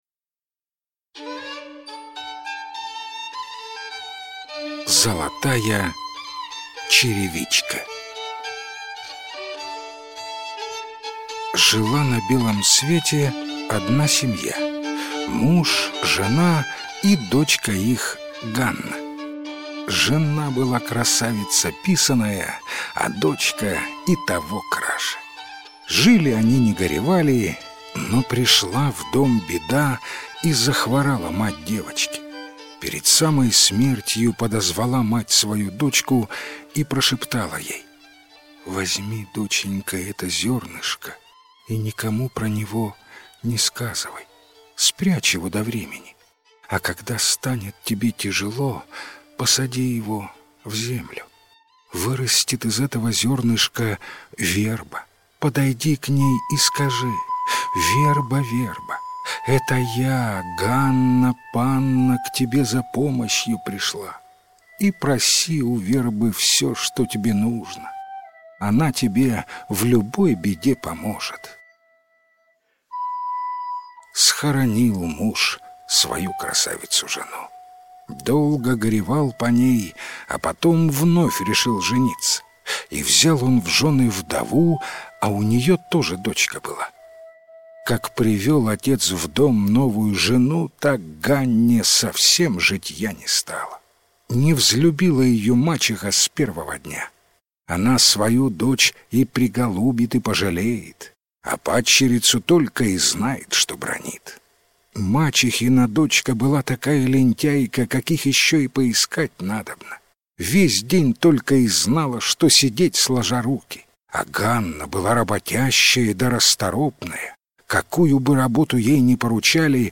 Золотая черевичка - украинская аудиосказка - слушать онлайн